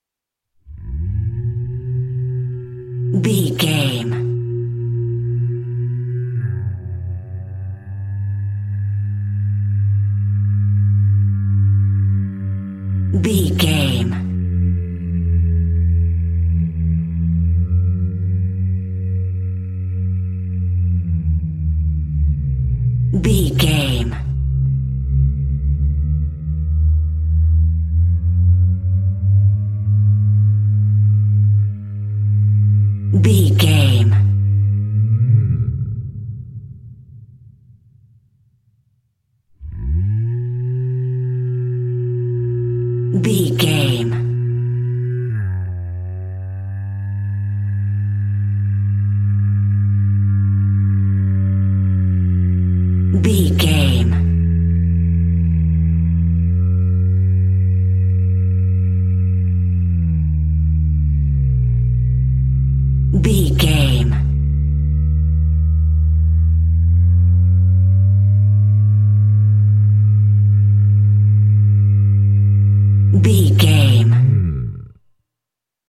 Dinosaur call whale close monster with without rvrb
Sound Effects
scary
ominous
dark
eerie